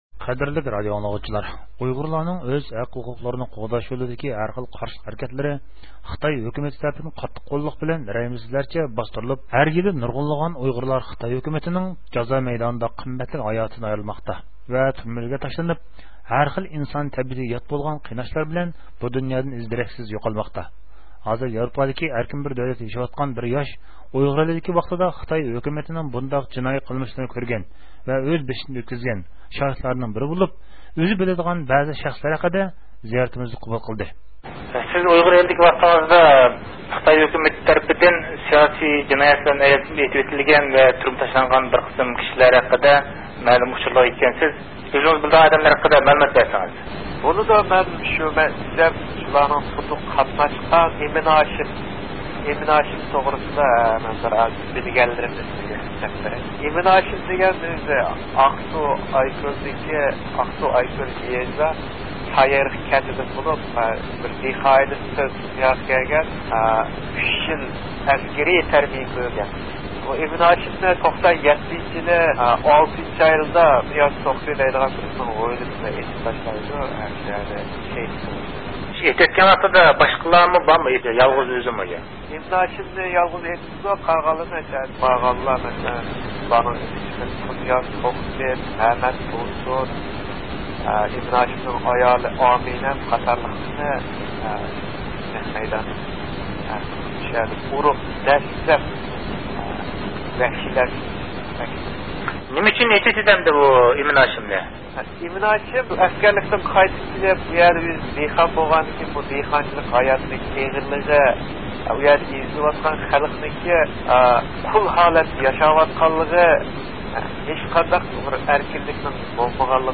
ھازىر ياۋروپادىكى ئەركىن بىر دۆلەتتە ياشاۋاتقان بىر ياش ئۇيغۇر ئېلىدىكى ۋاقتىدا خىتاي ھۆكۈمىتىنىڭ بۇنداق جىنايى قىلمىشلىرىنى كۆرگەن ۋە ئۆز بېشىدىن ئۆتكۈزگەن شاھىتلارنىڭ بىرى بولۇپ، ئۆزى بىلىدىغان بەزى شەخىسلەر ھەققىدە زىياتىمىزنى قوبۇل قىلدى.